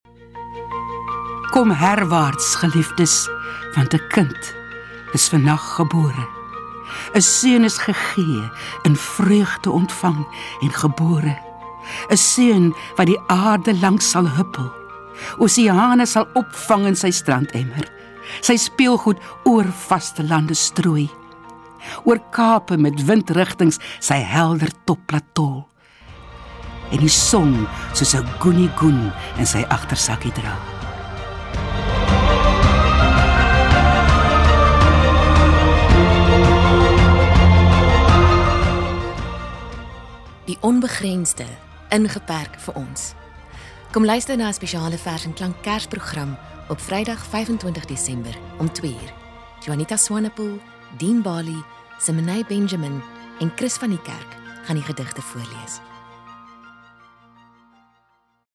14:00 Vers & Klank (Verse en musiek wat bemoedig en bevestig hoekom hierdie moeë mensdom, selfs na ‘n jaar soos hierdie, steeds kan sê: God is mét ons)